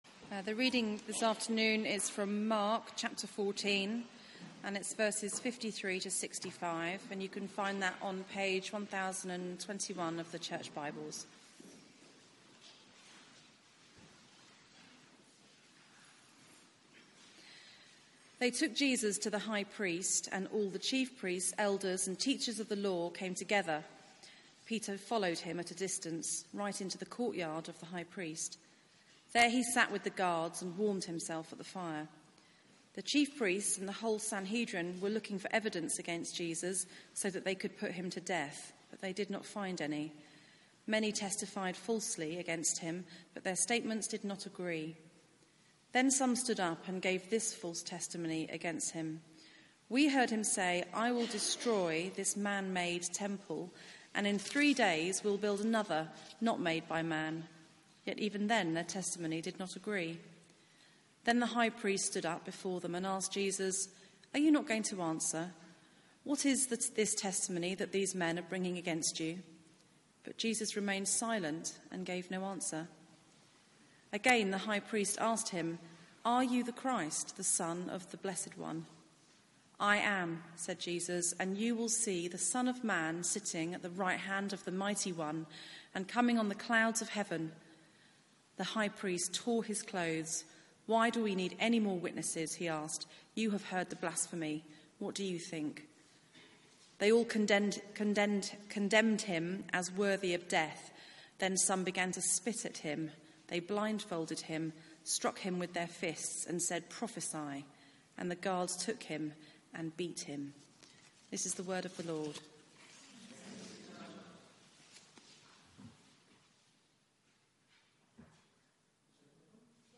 Media for 4pm Service on Sun 25th Feb 2018 16:00
ReadingsPlay